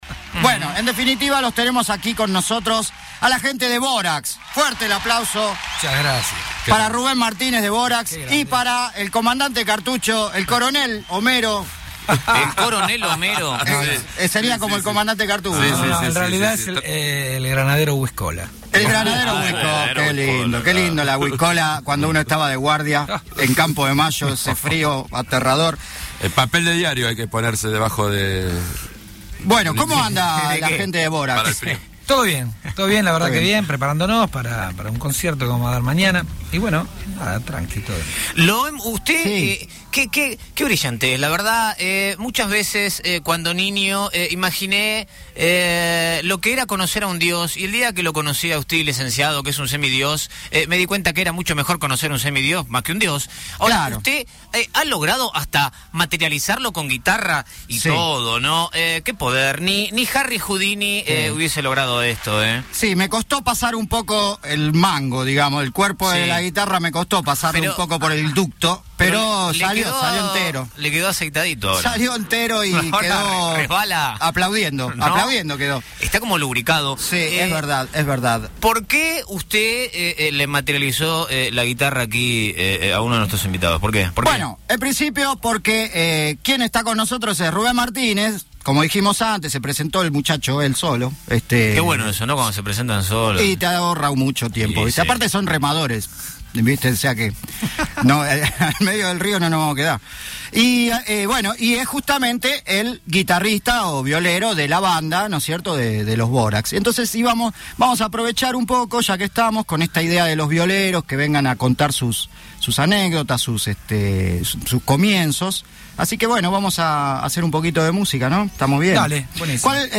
Además trajo la guitarra y tocó alcunas cancones de la banda.